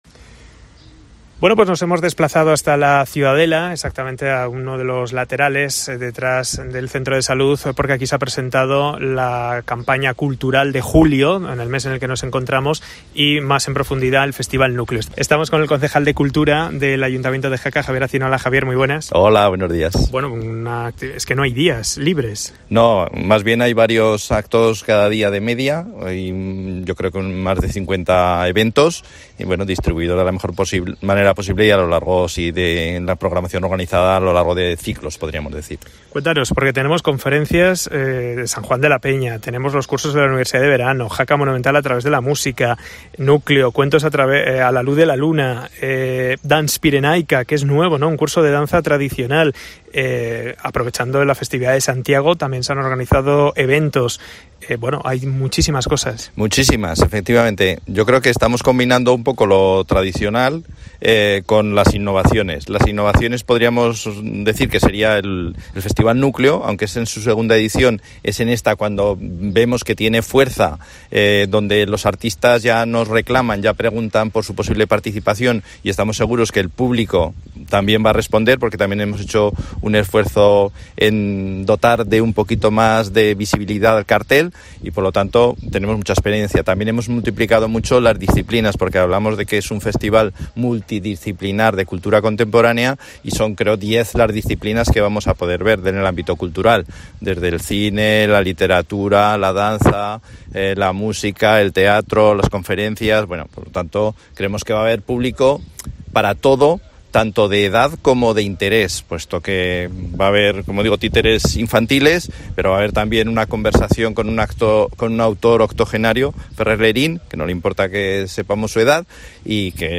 El concejal de cultura Javier Acín explica toda la agenda cultural de julio